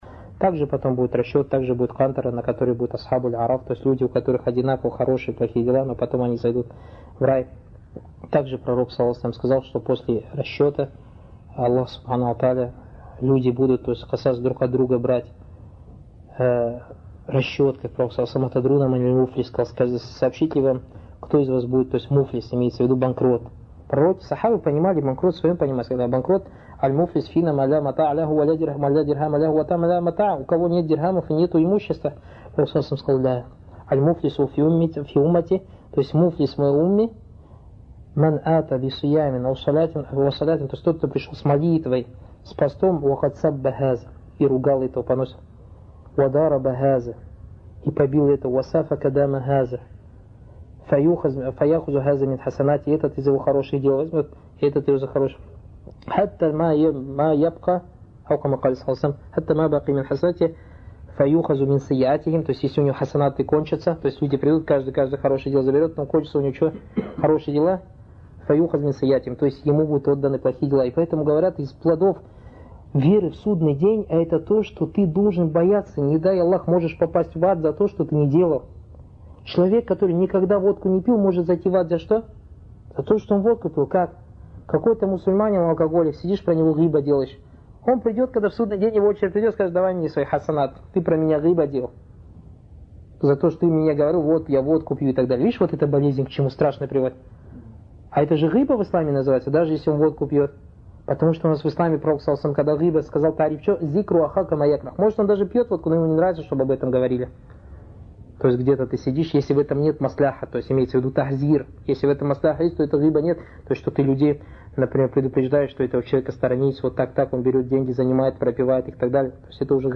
Чтение книги, посвященной столпам веры в Исламе и как их практиковать в жизни мусульман.